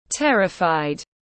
Terrified /ˈterɪfaɪd/